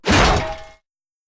Melee Weapon Attack 10.wav